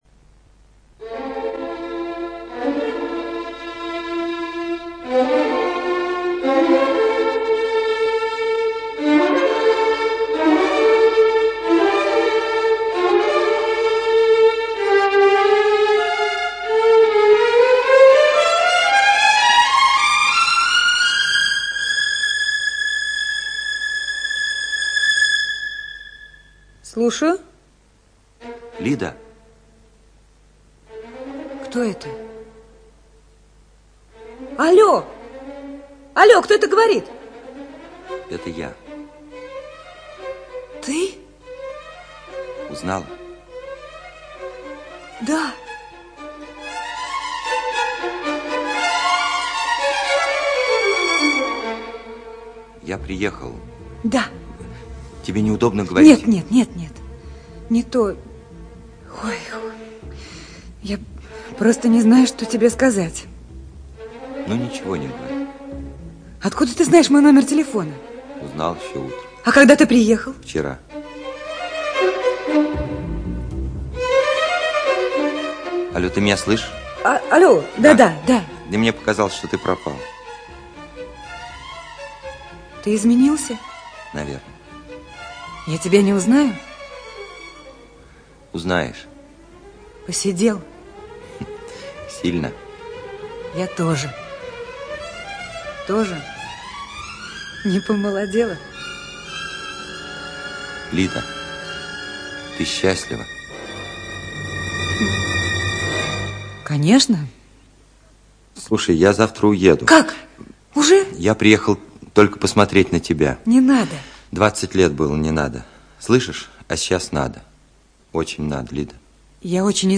ЧитаютПельтцер Т., Васильева В., Покровская А., Табаков О., Баталов А.
ЖанрРадиоспектакли